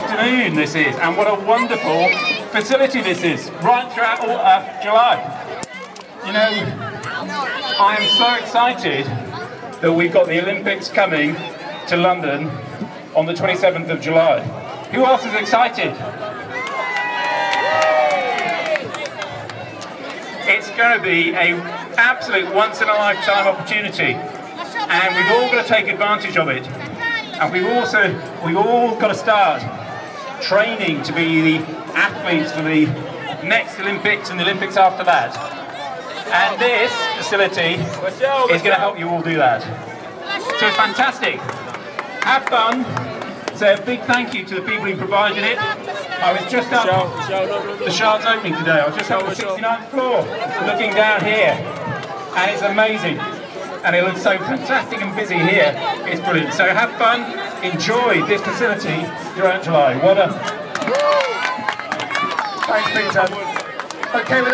Cllr Peter John at opening of Pop Up Mini Olympics